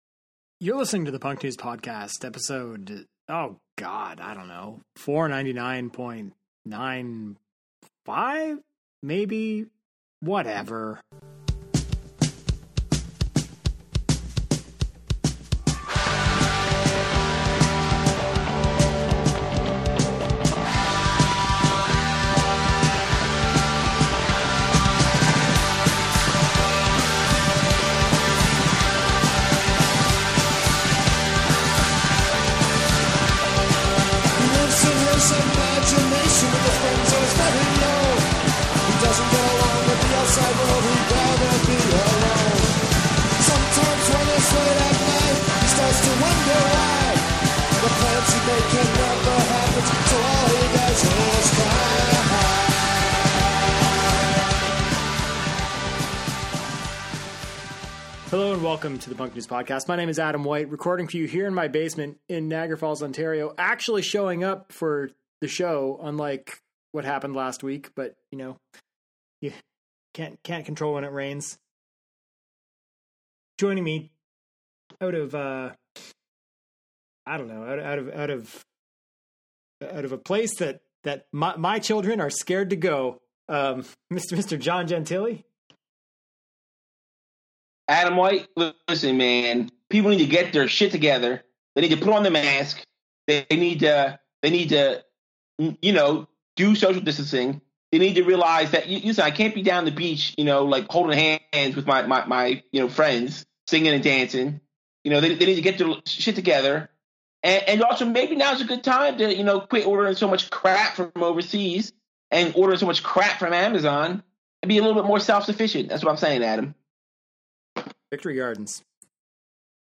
Audio problems!